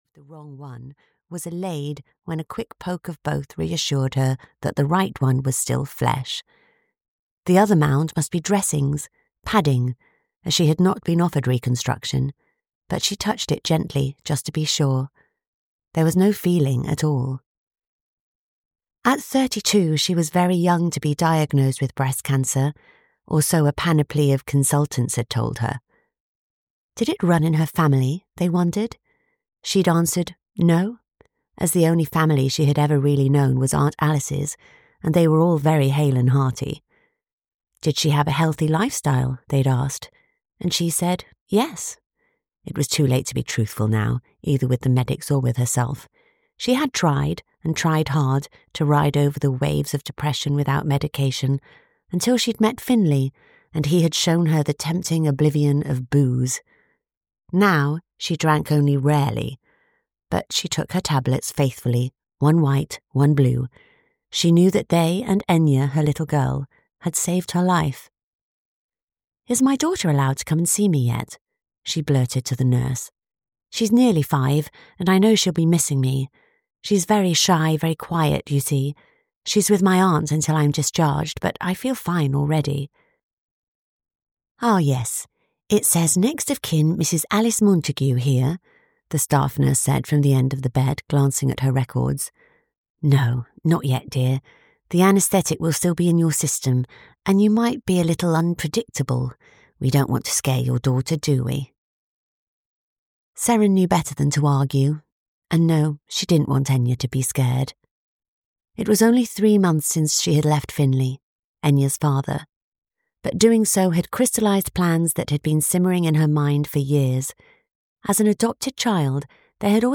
Audio knihaThe Lighthouse Keeper of Anglesey (EN)
Ukázka z knihy